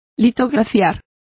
Complete with pronunciation of the translation of lithographed.